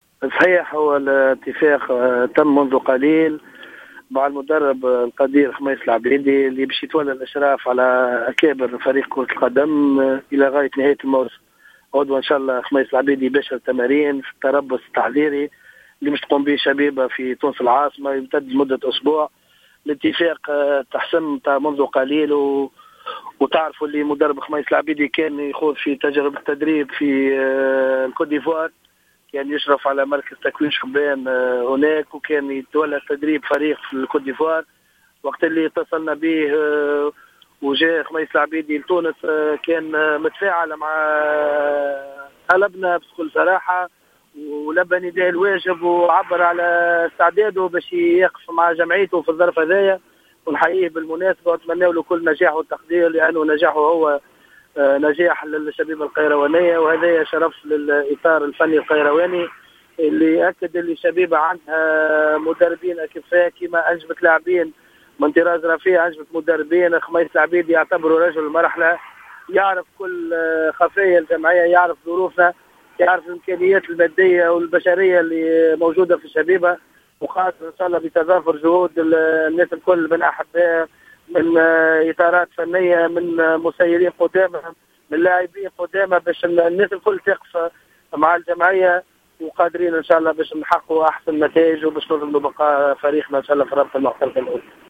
في إتصال هاتفي مع راديو جوهرة أف أم